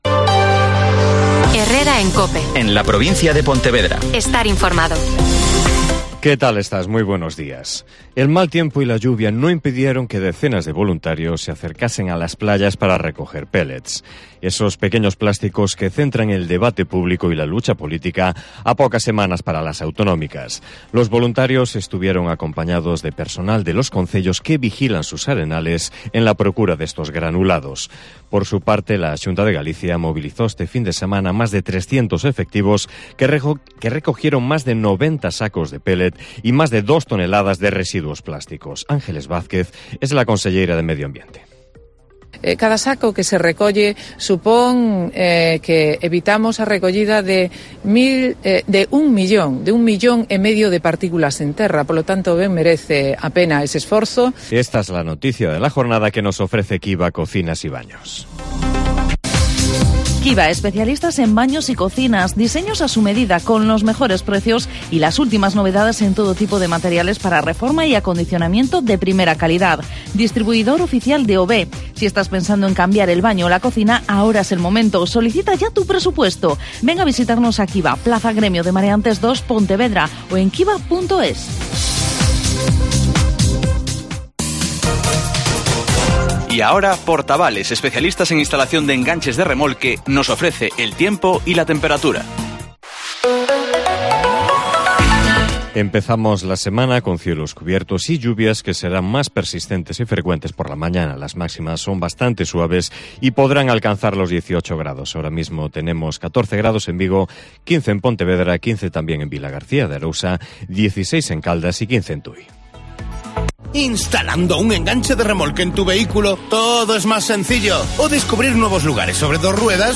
Herrera en COPE en la Provincia de Pontevedra (Informativo 08:24h)